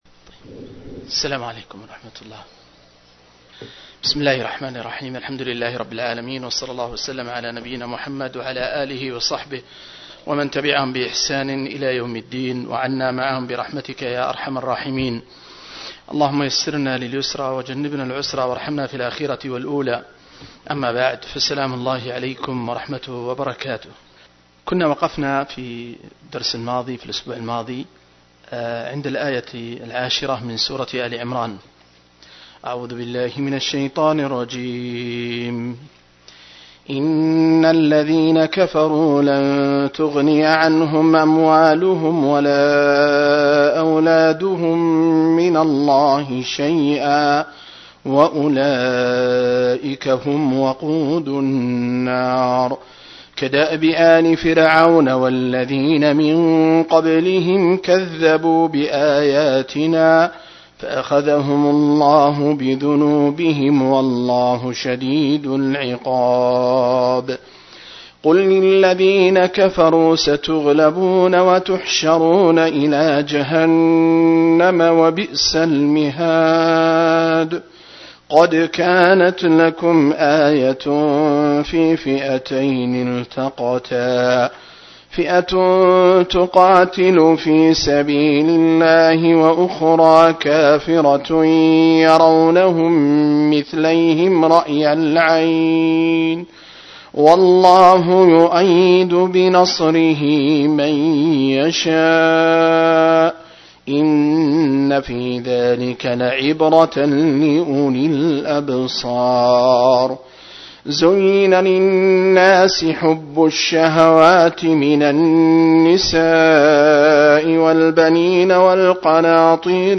062- عمدة التفسير عن الحافظ ابن كثير رحمه الله للعلامة أحمد شاكر رحمه الله – قراءة وتعليق –